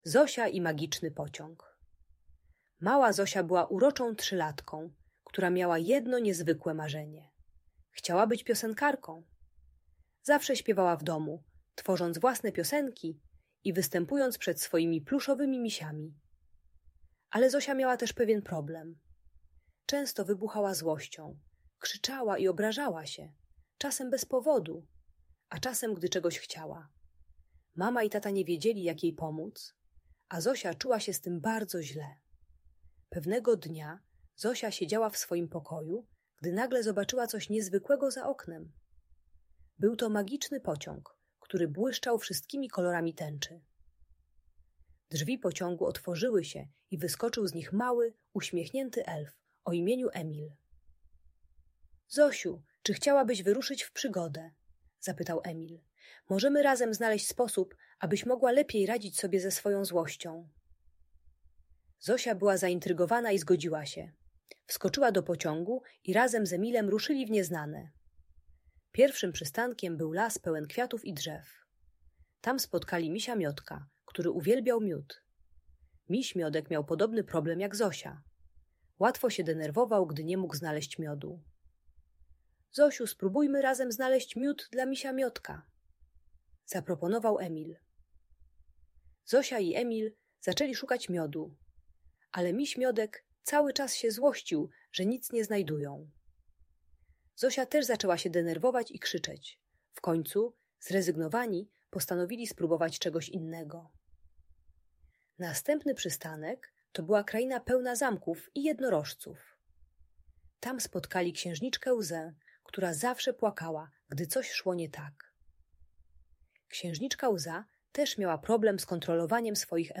Zosia i Magiczny Pociąg - Opowieść o emocjach i marzeniach - Audiobajka